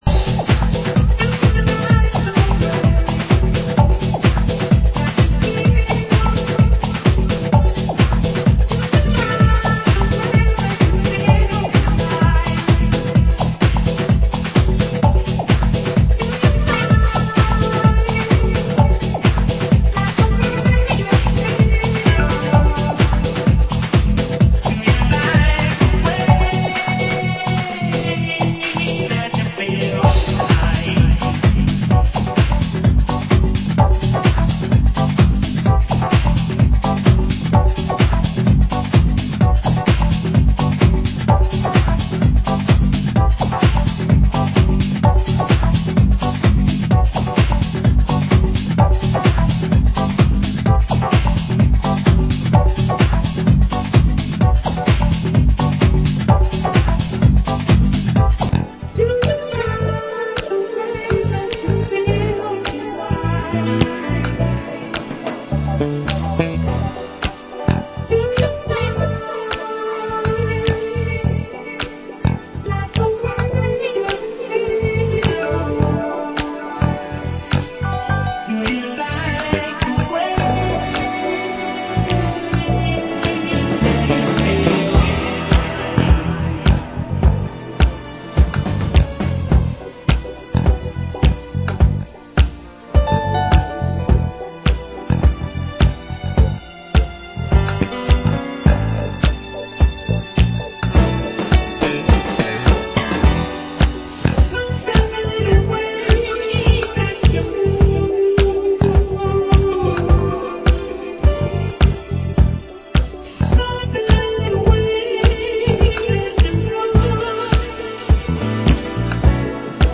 Genre: Deep House